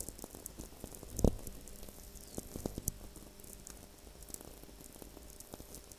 Cumiana, NW Italy